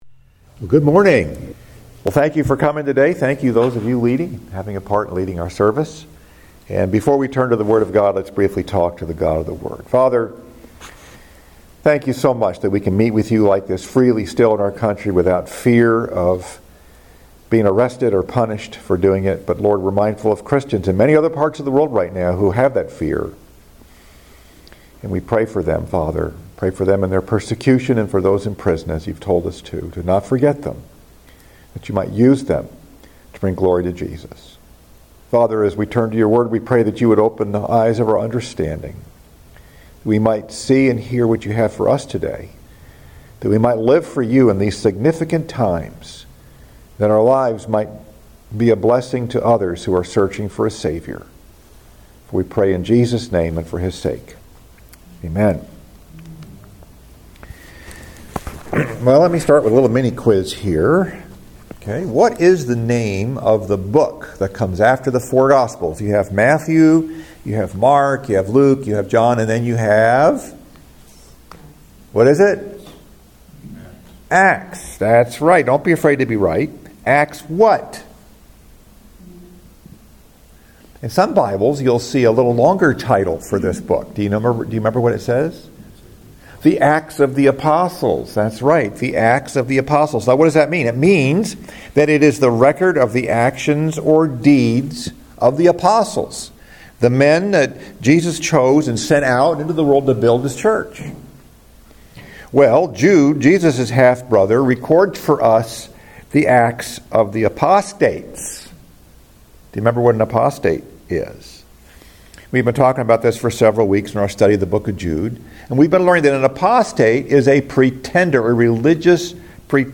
Message: “Acts of the Apostates” Scripture: Jude 8-10